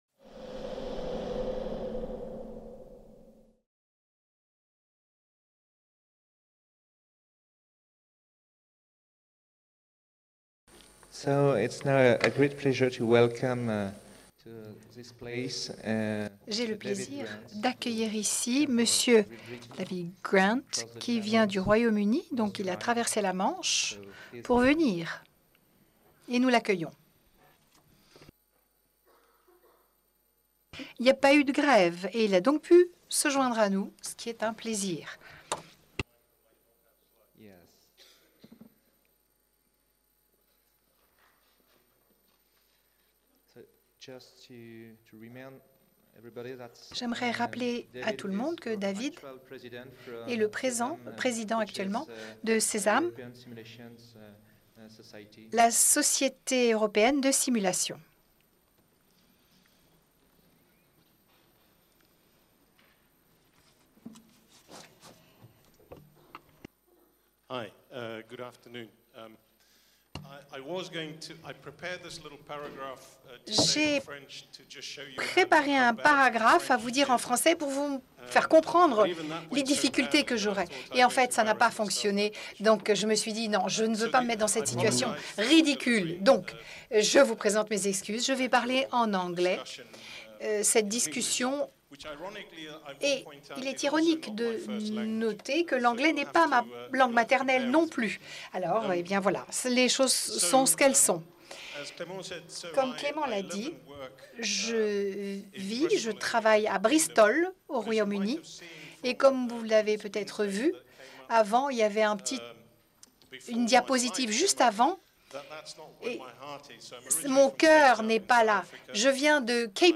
SOFRASIMS 2018 | 11 - Facteur humain / CRM en simulation (trad. française) | Canal U